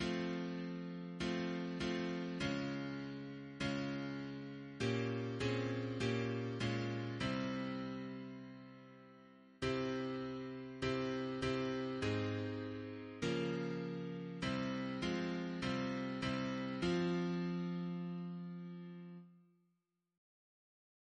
Double chant in F minor Composer: James Turle (1802-1882), Organist of Westminster Abbey Note: after Purcell Reference psalters: ACB: 345; CWP: 147; H1940: 697; H1982: S221; PP/SNCB: 218; RSCM: 147